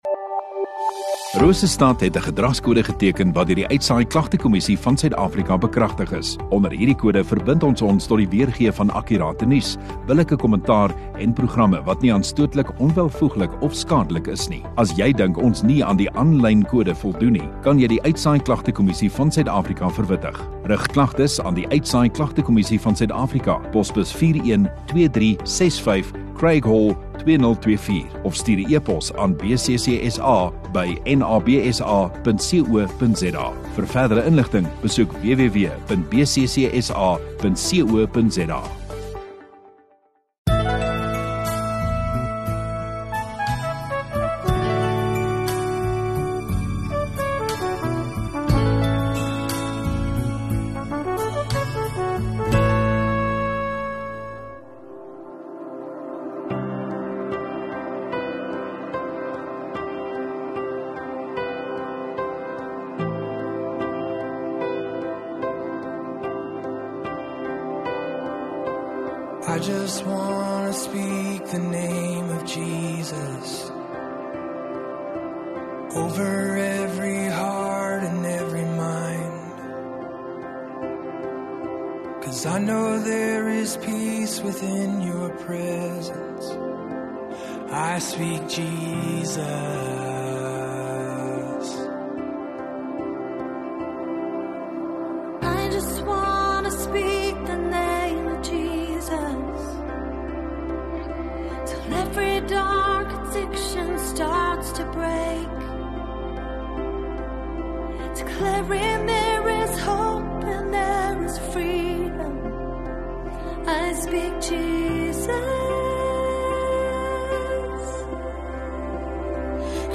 18 Apr (Goeie Vrydag) Vrydagoggend Erediens